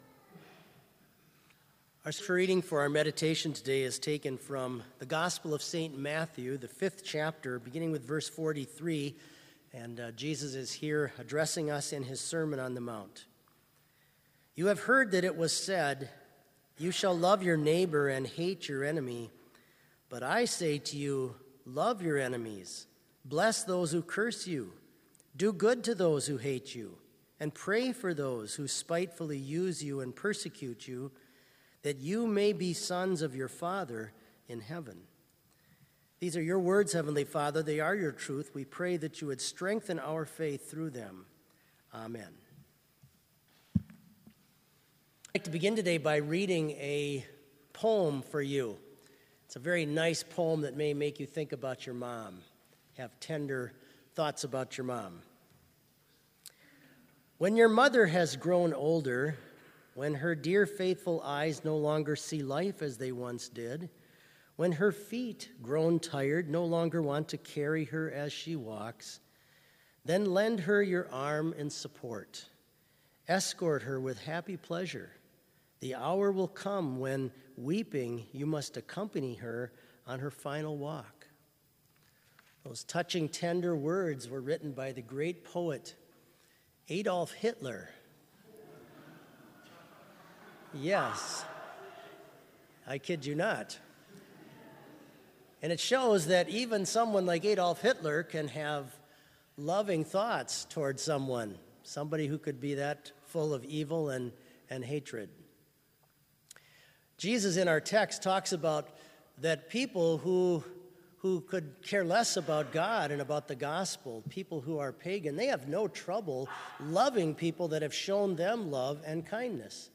Complete service audio for Chapel - September 17, 2019